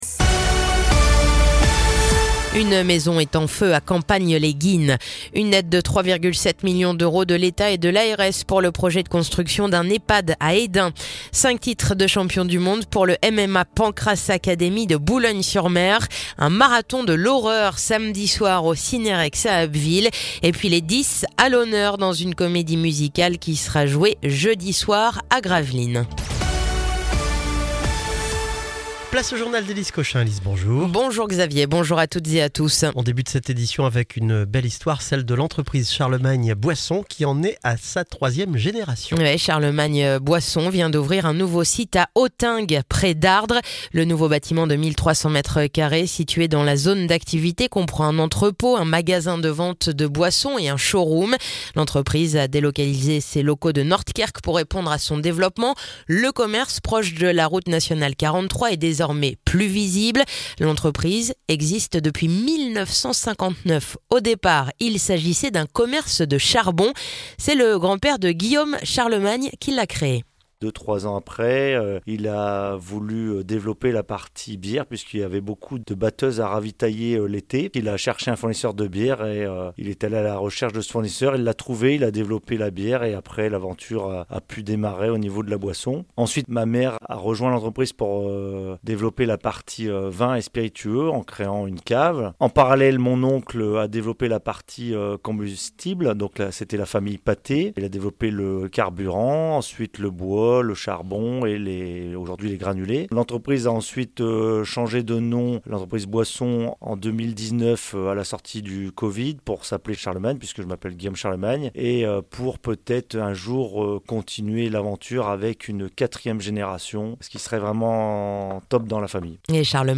Le journal du mardi 22 octobre